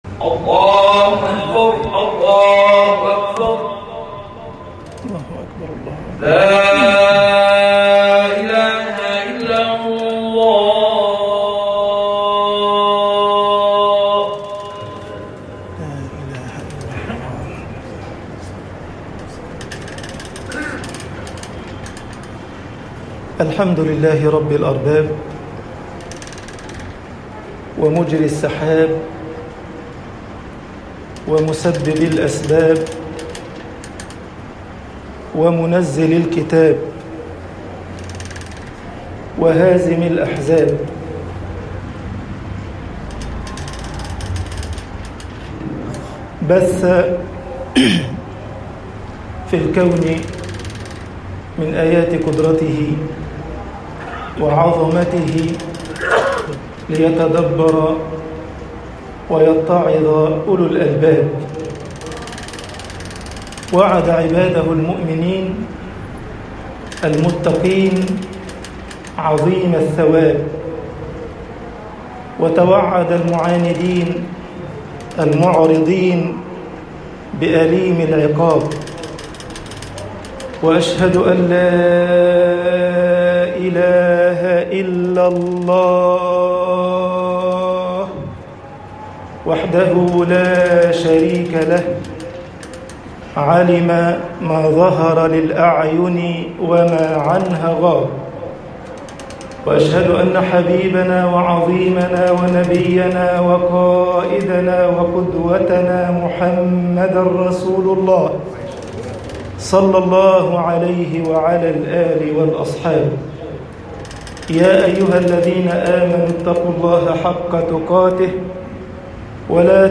خطب الجمعة - مصر الاستغفار مِمْحَاةٌ للذُّنوب طباعة البريد الإلكتروني التفاصيل كتب بواسطة